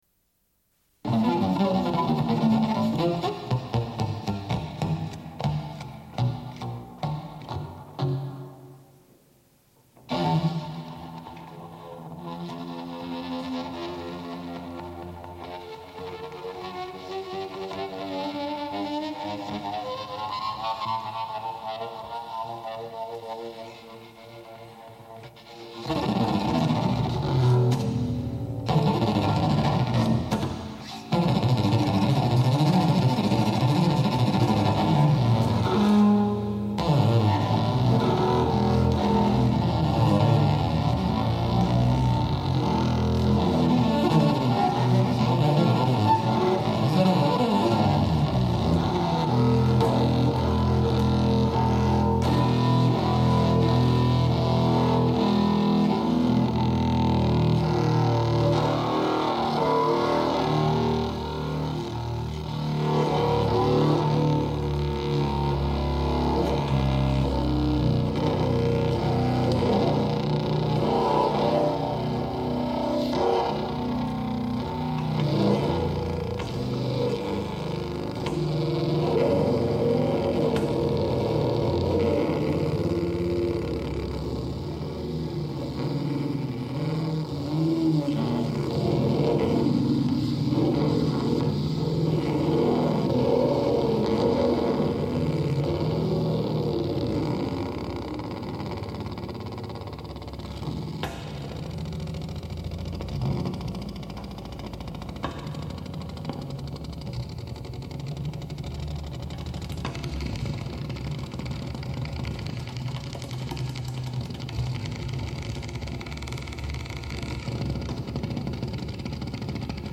Une cassette audio, face A31:20